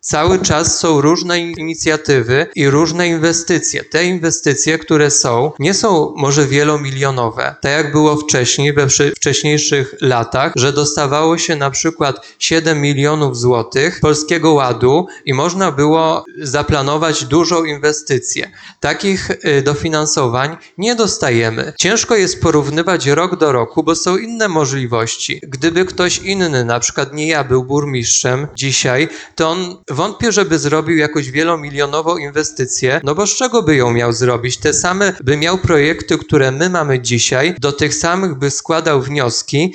W odpowiedzi na te zarzuty burmistrz Radłowa zwrócił wagę na ograniczone możliwości finansowe.